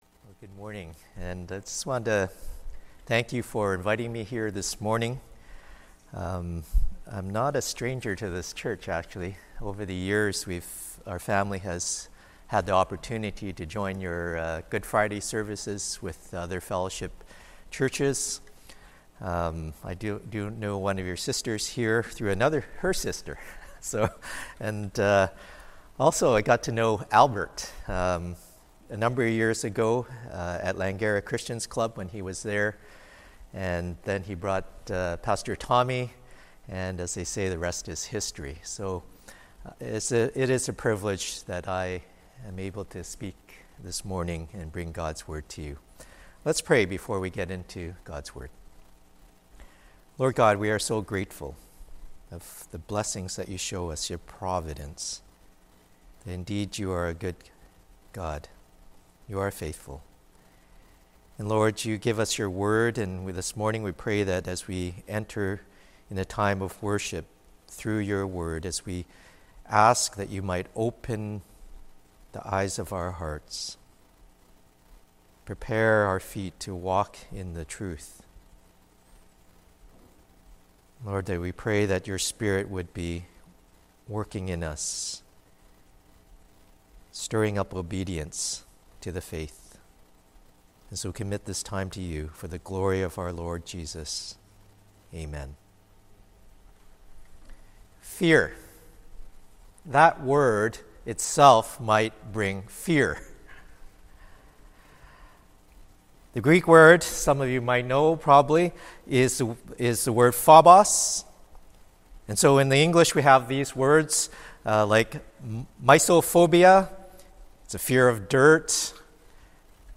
Sermons | Oakridge Baptist Church